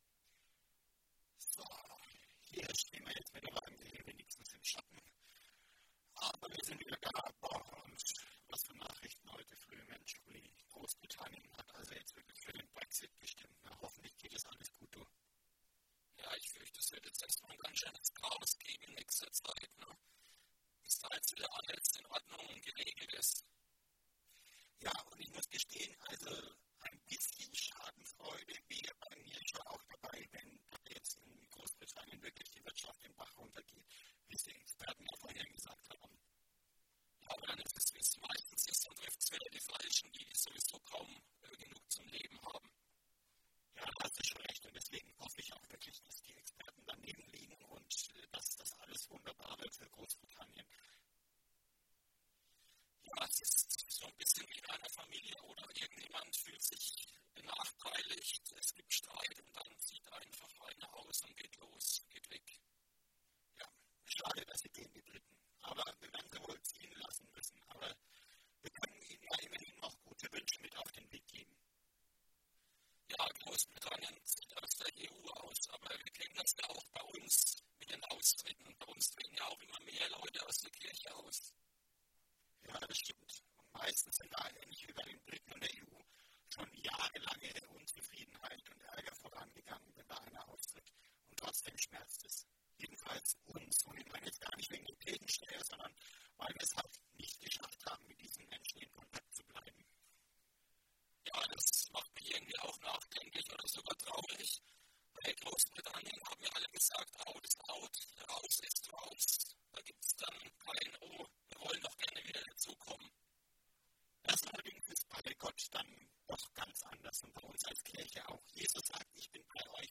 Fußgängerzone. Kurze Impulse zum Nachdenken fürs Wochenende.